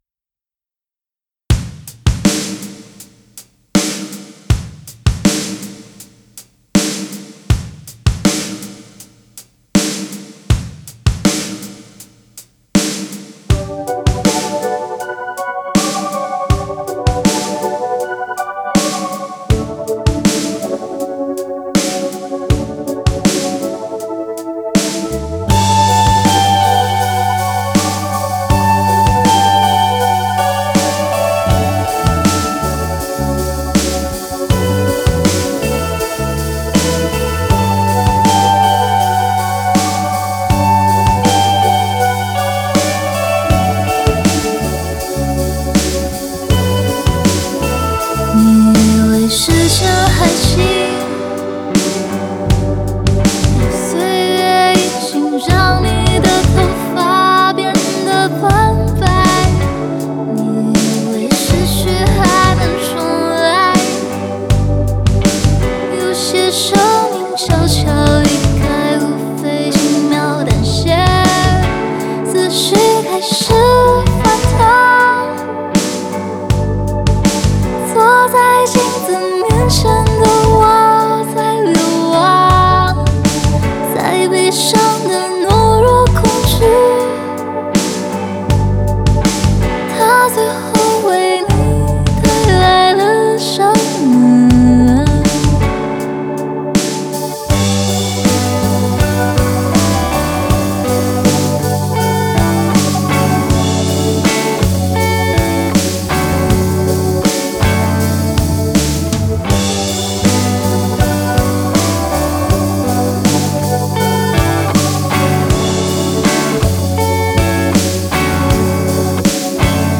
Ps：在线试听为压缩音质节选，体验无损音质请下载完整版
吉他
贝斯